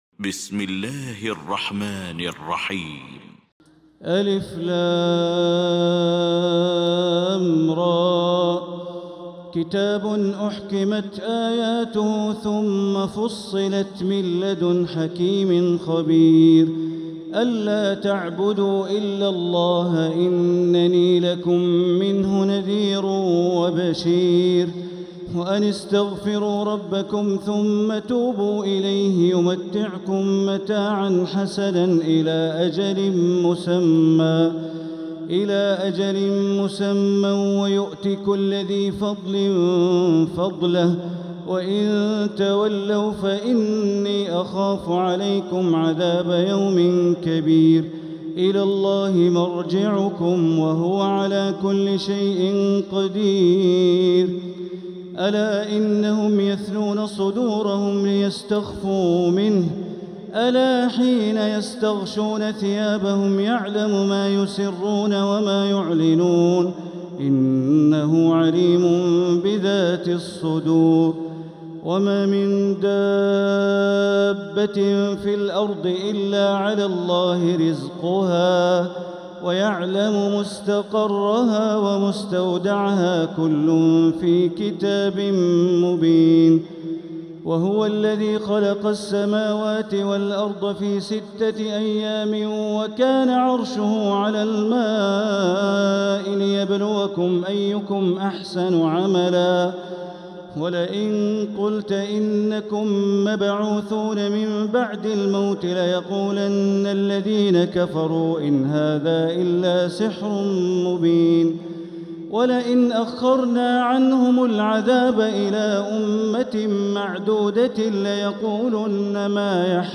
المكان: المسجد الحرام الشيخ: فضيلة الشيخ د. الوليد الشمسان فضيلة الشيخ د. الوليد الشمسان معالي الشيخ أ.د. بندر بليلة فضيلة الشيخ عبدالله الجهني هود